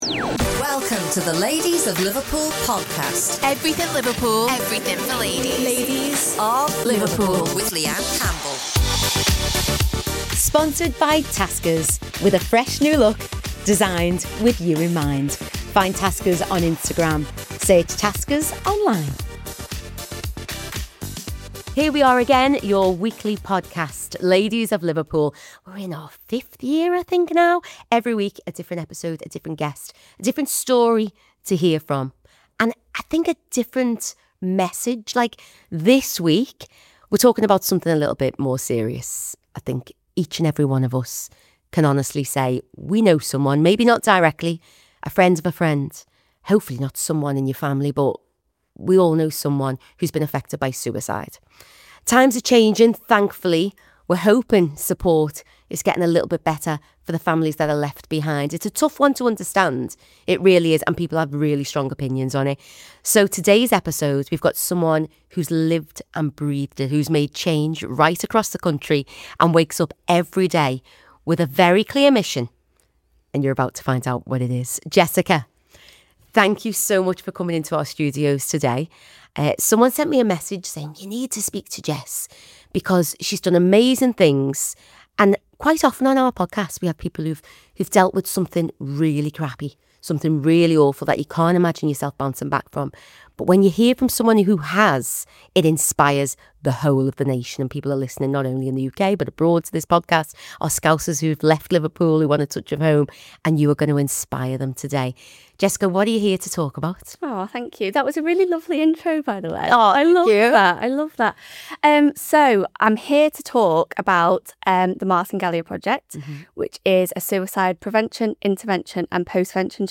This conversation is raw, moving, and full of hope.